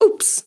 Mujer confundida: ¡Ups!
confusión
exclamación
mujer
Sonidos: Voz humana